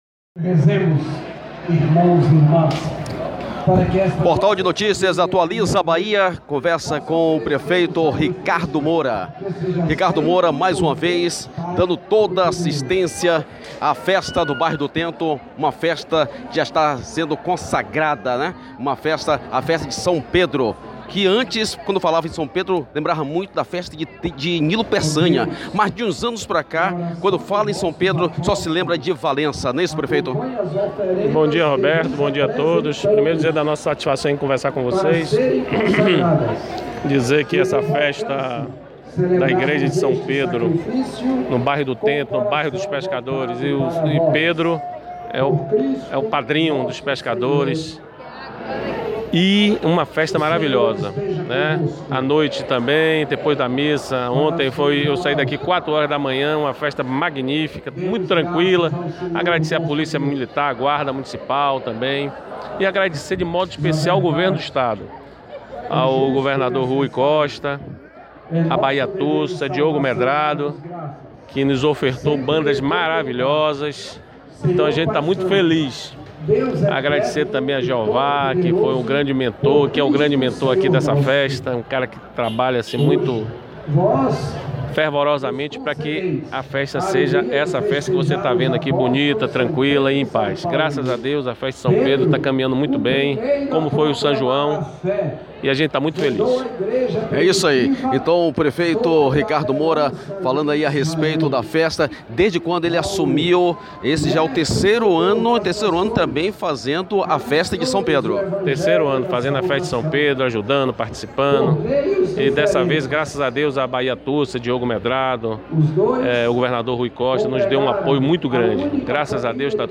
O Deputado Federal, Raimundo Costa, esteve presente e relembrou ao Atualiza Bahia a sua passagem pela presidência da comissão da festa:
O prefeito Ricardo Moura citou as contribuições do município e estado para a festa.